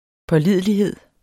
Udtale [ pʌˈliðˀəliˌheðˀ ]